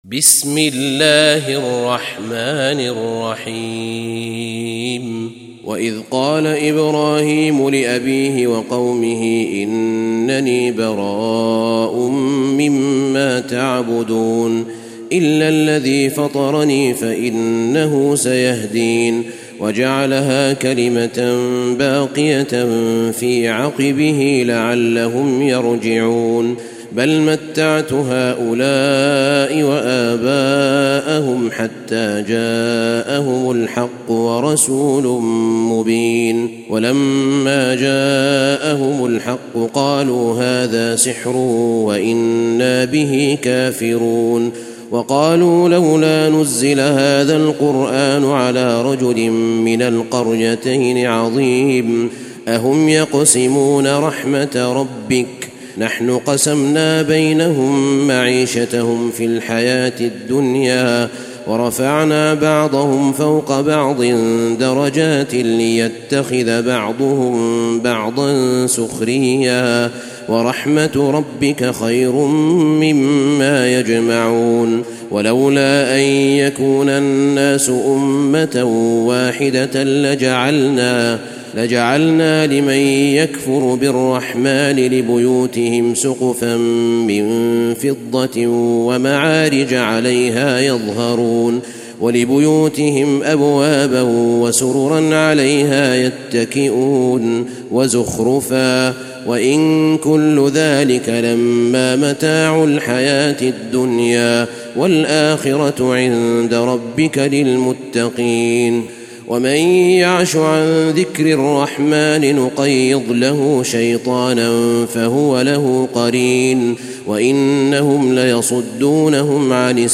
تلاوة سورة الزخرف
تاريخ النشر ١ محرم ١٤٣٤ هـ المكان: المسجد النبوي الشيخ: فضيلة الشيخ أحمد بن طالب بن حميد فضيلة الشيخ أحمد بن طالب بن حميد سورة الزخرف